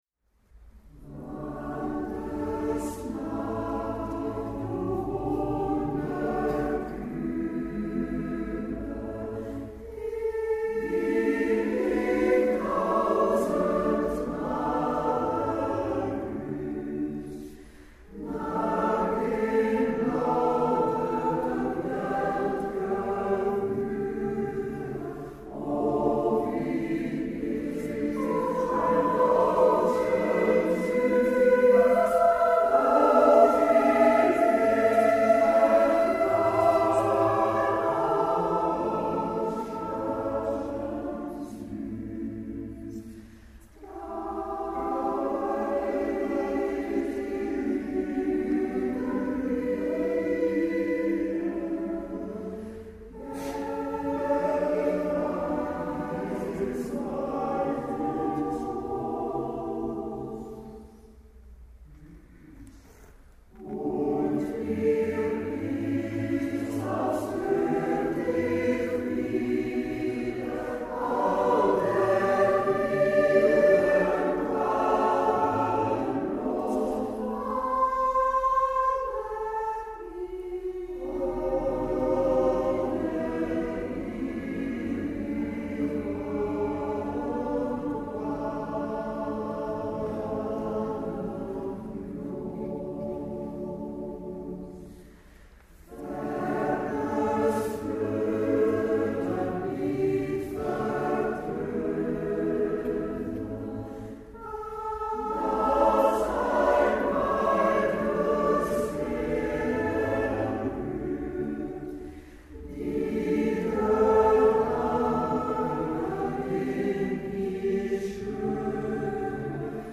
Bedankt voor jullie aanwezigheid op ons concert